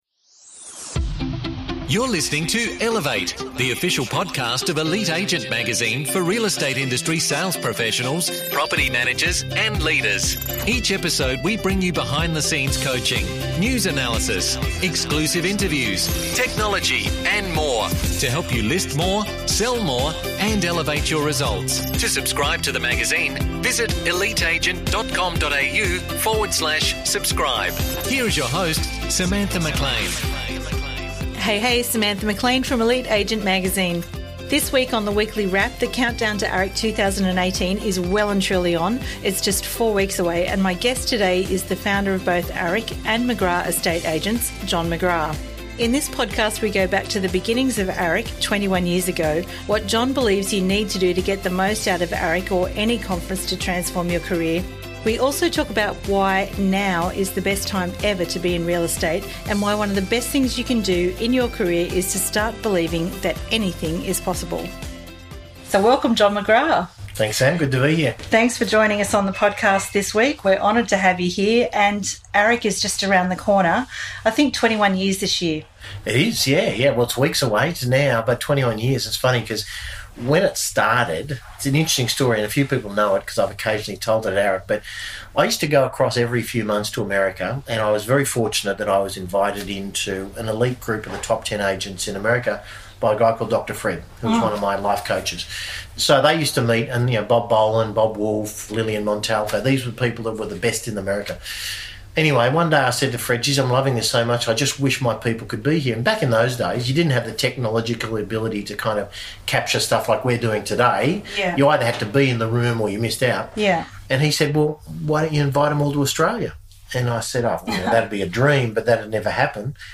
Guest Interview